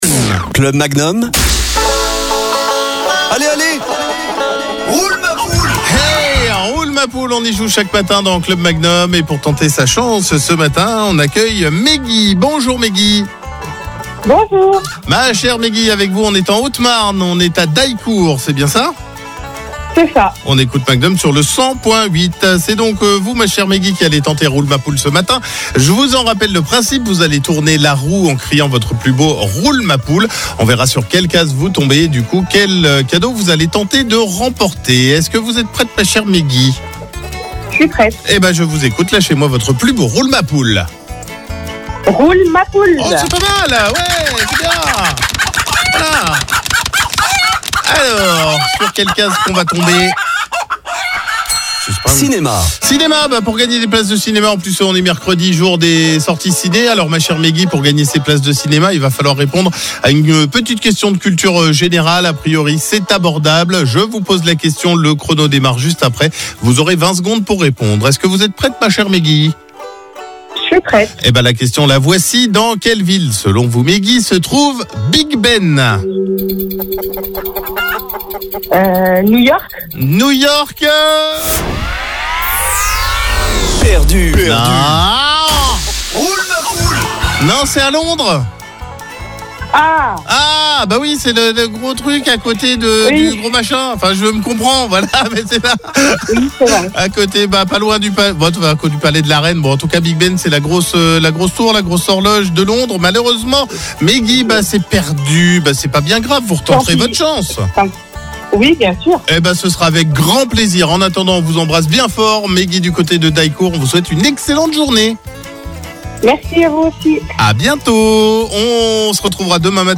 Tournez la roue en criant « Roule ma poule » , plus vous criez fort, plus la roue va tourner.